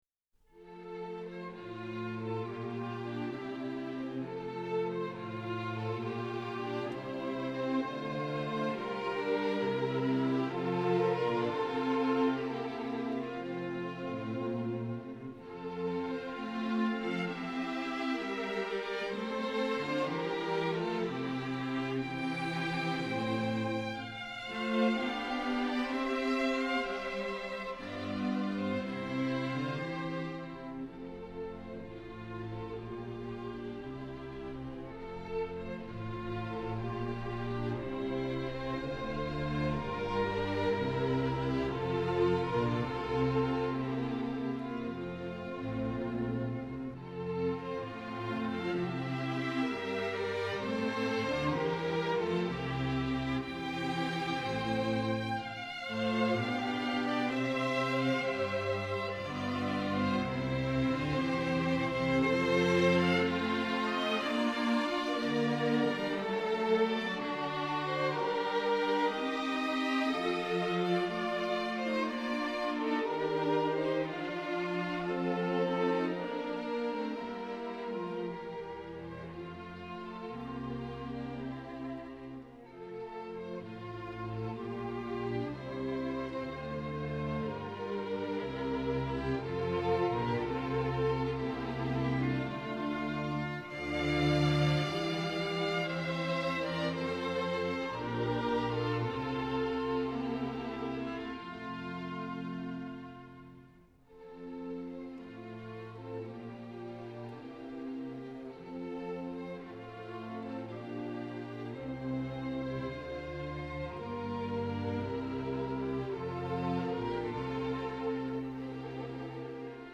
An "aria" in music usually describes an expressive melody.